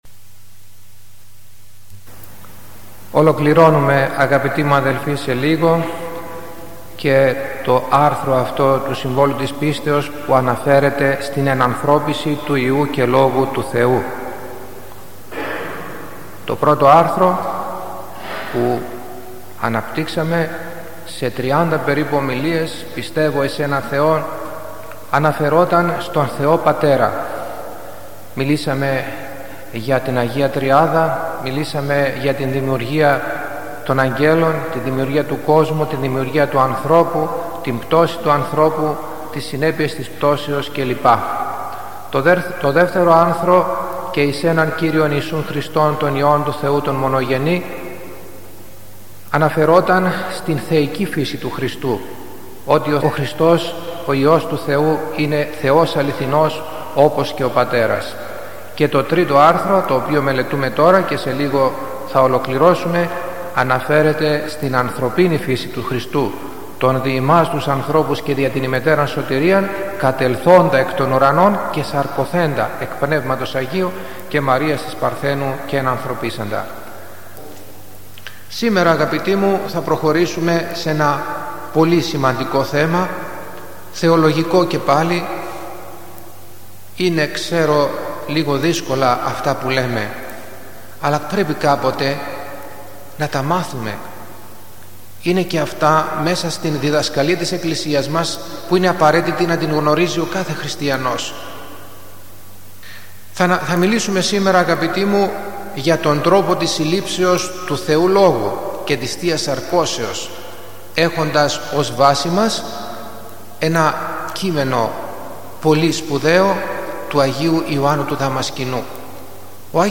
Περί της θείας σαρκώσεως-Οι τρεις γεννήσεις – Ομιλία στο Σύμβολο της Πίστεως